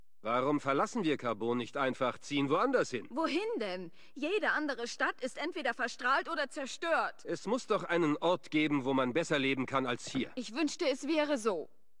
FOBOS-Dialog-Carbon-Bürger-004.ogg